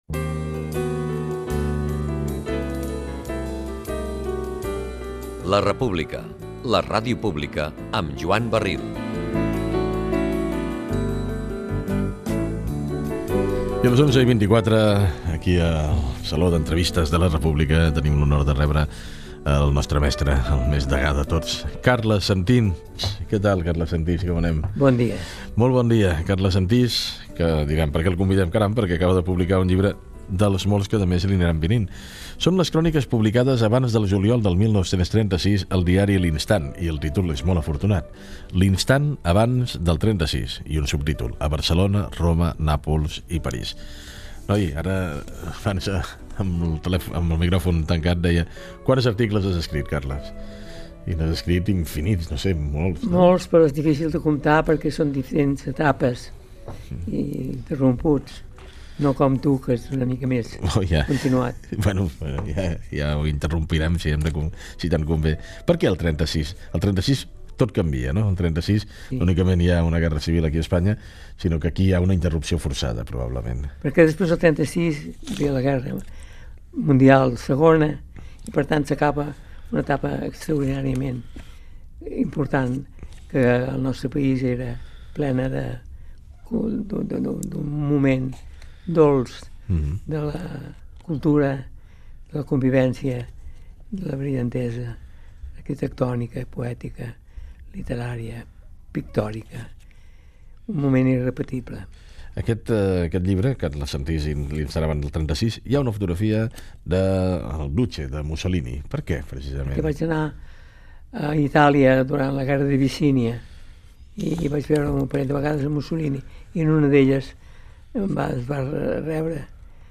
Identificació del programa, hora, "El saló d'entrevistes" amb el periodista Carles Sentís.
Info-entreteniment